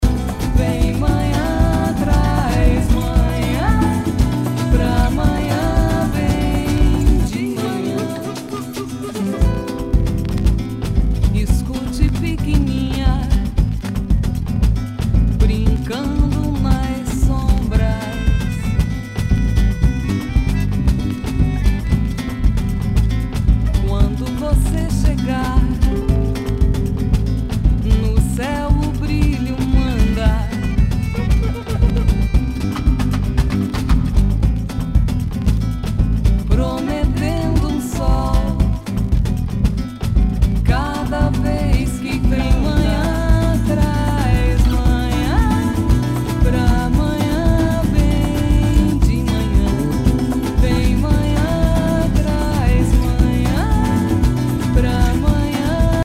Future Jazz！
Nu- Jazz/BREAK BEATS
全体にチリノイズが入ります